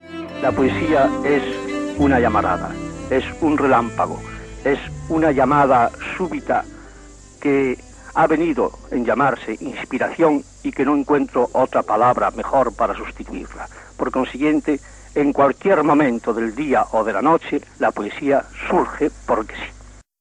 1968. Visão de XMAB sobre a poesia no programa "Poetas de Galicia" de Radio Nacional de España